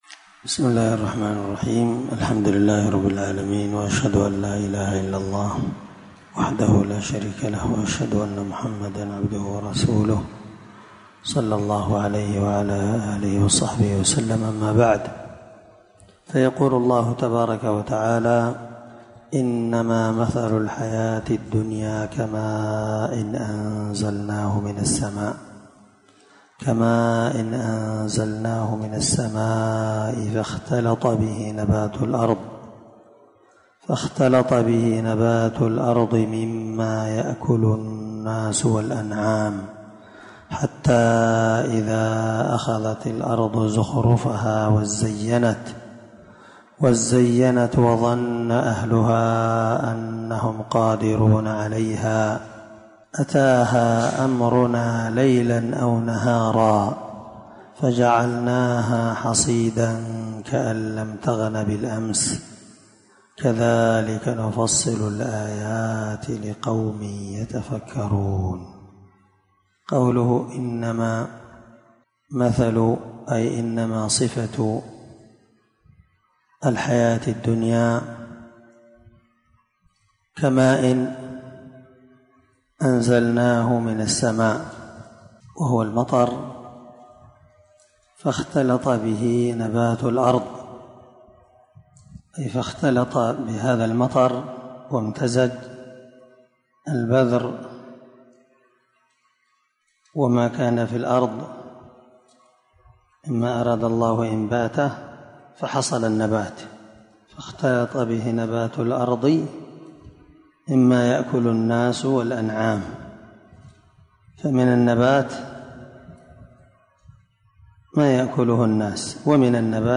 594الدرس 10 تفسير آية (24) من سورة يونس من تفسير القران الكريم مع قراءة لتفسير السعدي
دار الحديث- المَحاوِلة- الصبيحة.